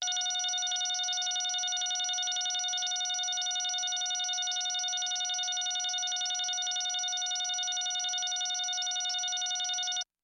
描述：通过Modular Sample从模拟合成器采样的单音。
Tag: F6 MIDI音符-90 罗兰木星-4 合成器 单票据 多重采样